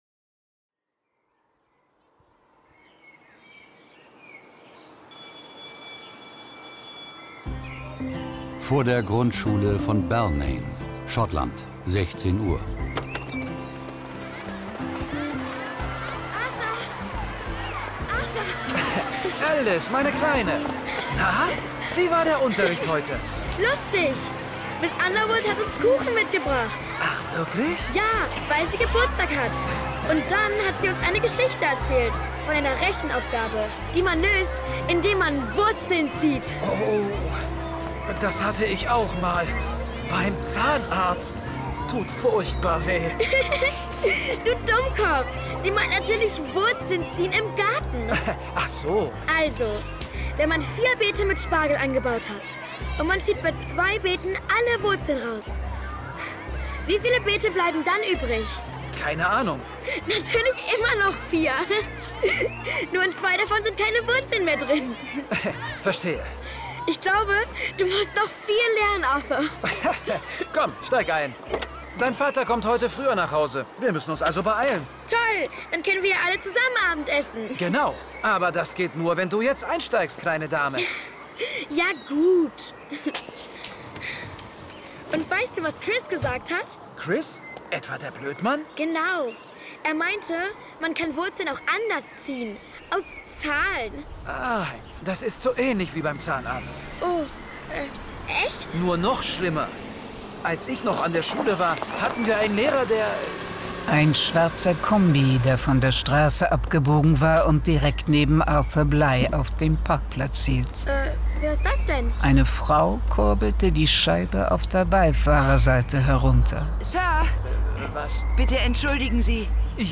John Sinclair Classics - Folge 33 Irrfahrt ins Jenseits. Hörspiel.